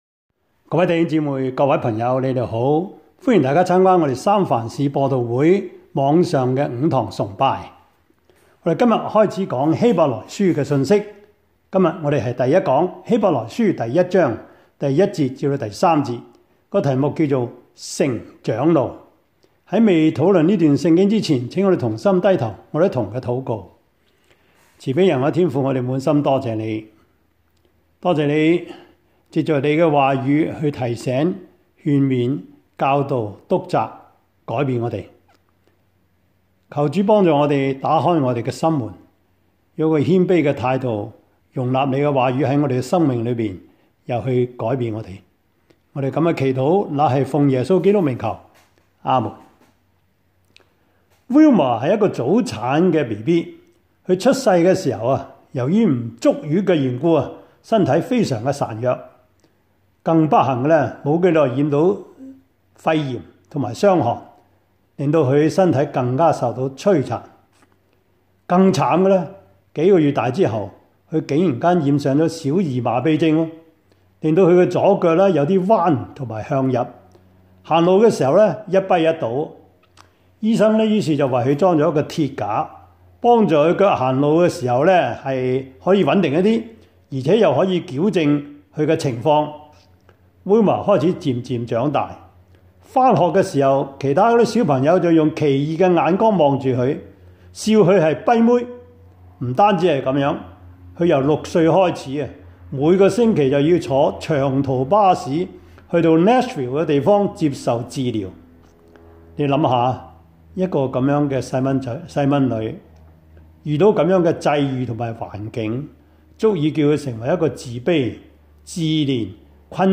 Series: 2021 主日崇拜
Service Type: 主日崇拜